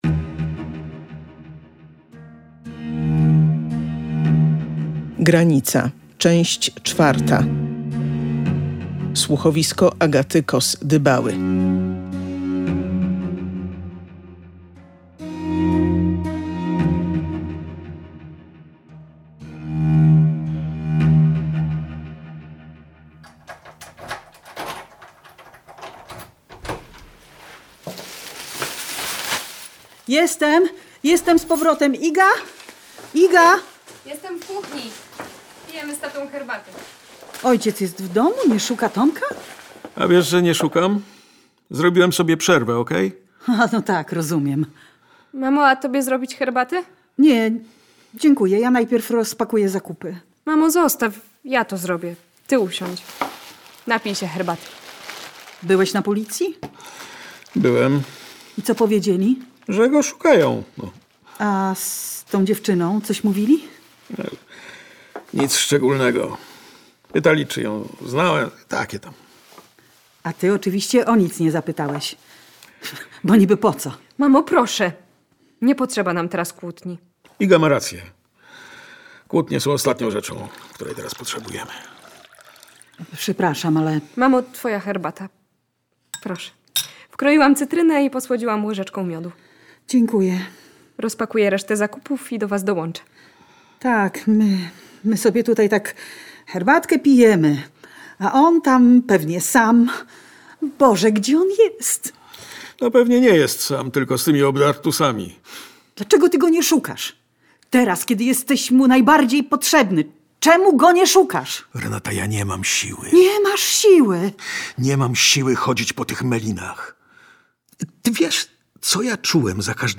Kto okaże się zabójcą dwóch narkomanek? I czy one będą ostatnimi ofiarami historii opowiedzianej w słuchowisku „Granica”?